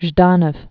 (zhdänəf)